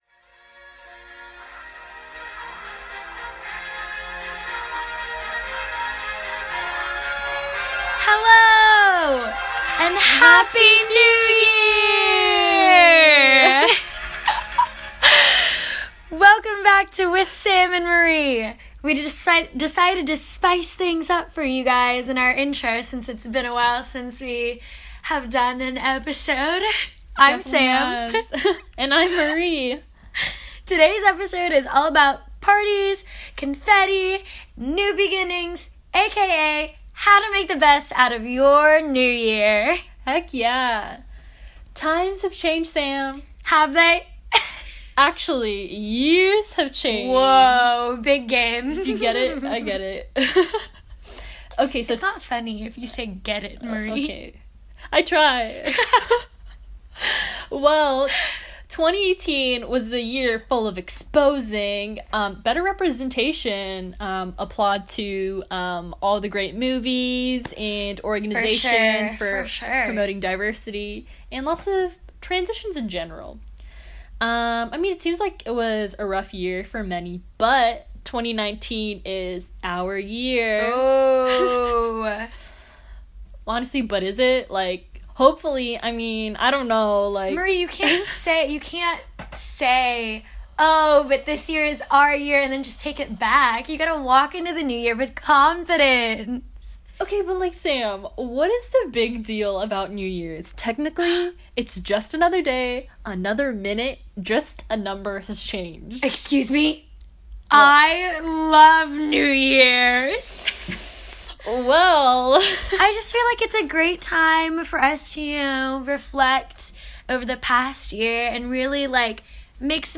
Listen closely and you can here me typing some notes on my laptop! Please excuse my monotone voice in the beginning–I’m just nervous, I swear it gets better! And of course no podcast is complete without messing up words, saying “like” five times in one sentence, and awkward laughs.